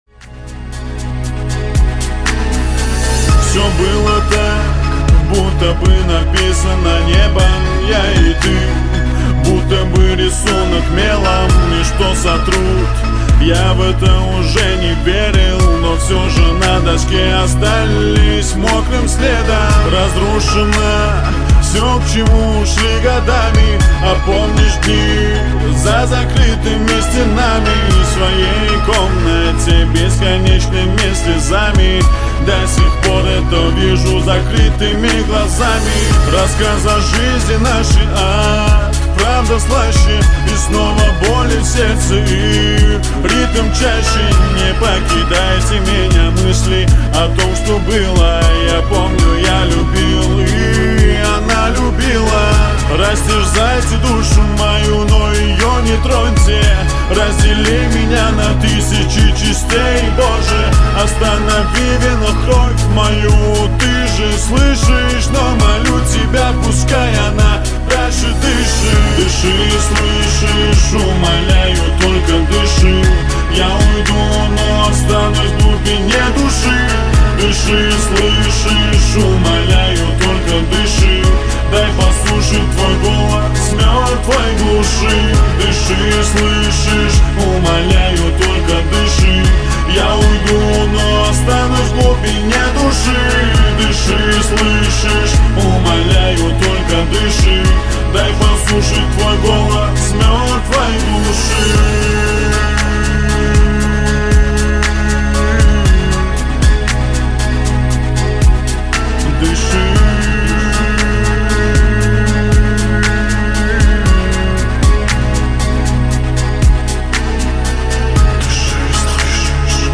slowed + reverb version 2026